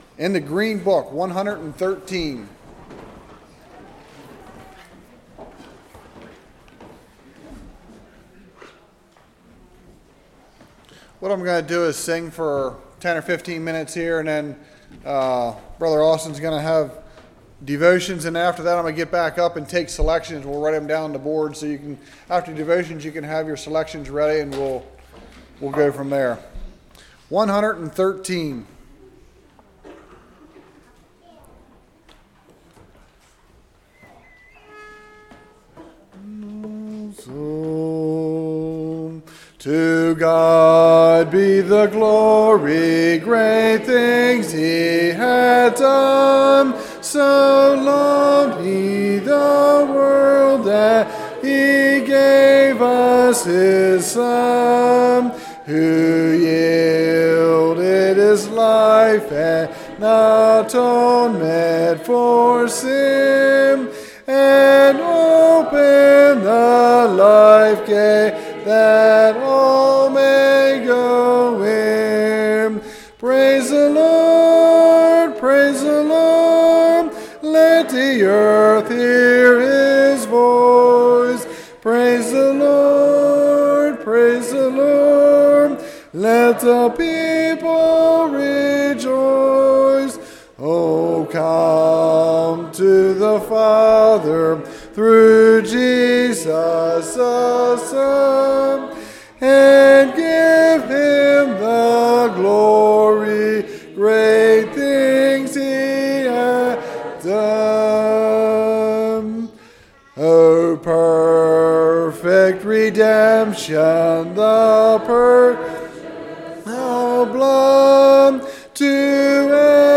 Hymn Sing